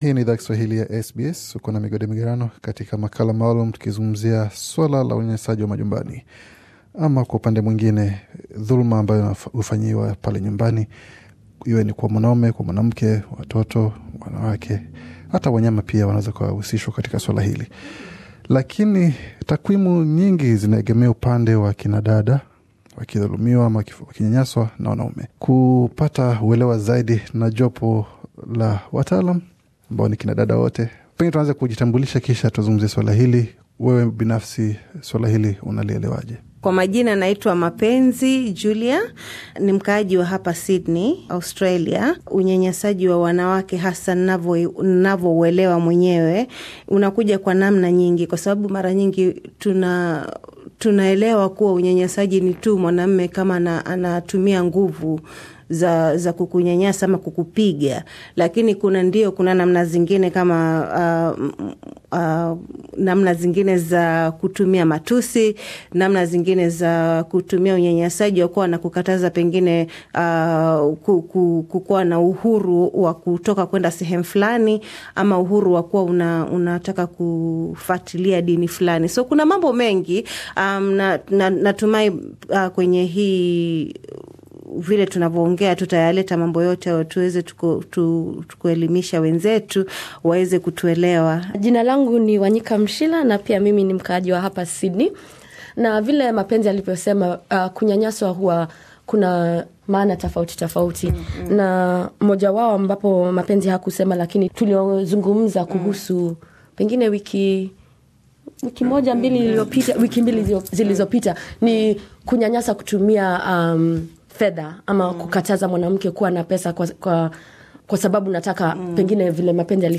Je ni mbinu gani bora yaku kabiliana na maswala kama haya? SBS Swahili ilizungumza na kina dada kutoka jamii ya mashariki ya Afrika ambao wali changia maoni yao kuhusu swala hili.